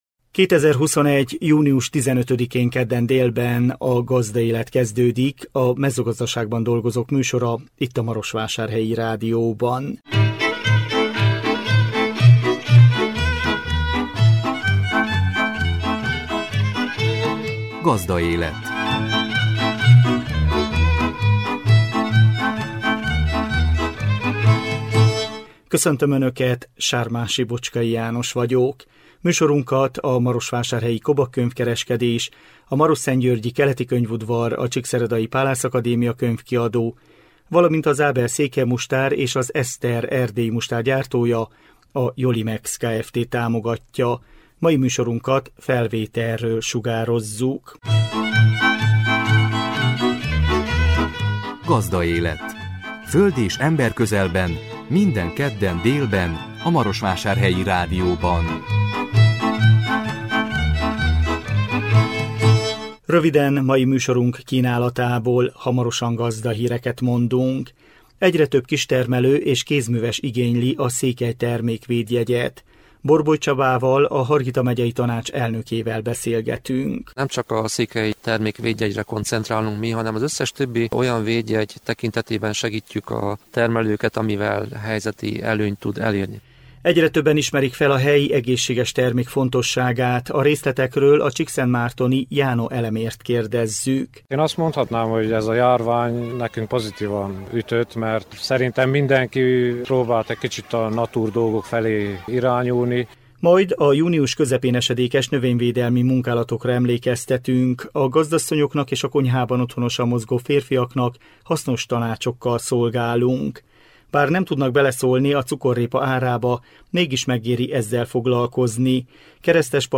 A 2021 június 15-én jelentkező műsor tartalma: Gazdahírek, Egyre több kistermelő és kézműves igényli a székely termék védjegyet. Borboly Csabával, a Hargita megyei Tanács elnökével beszélgetünk.
Keresztes Pap Géza csíkszentgyörgyi alpolgármester, cukorrépa termesztő válaszol kérdéseinkre.